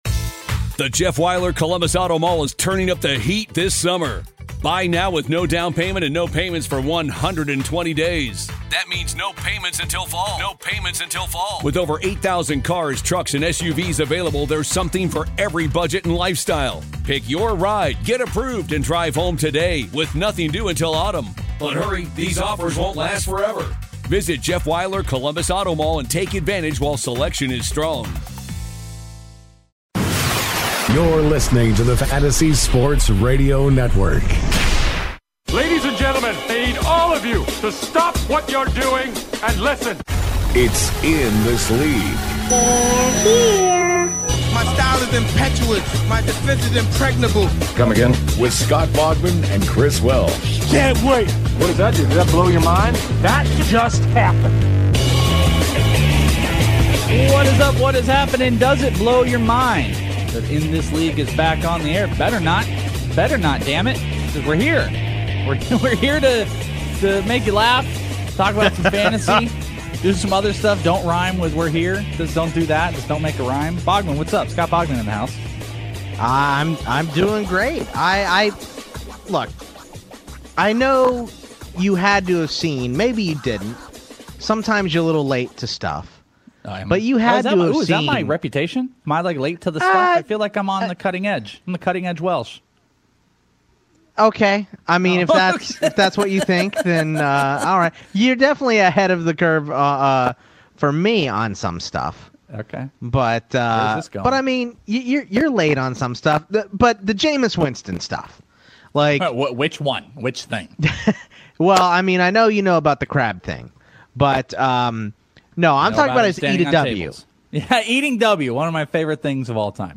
The guys talk with some callers about how to properly use the Amazon Echo, and how tell Alexa how to play FNTSY Radio!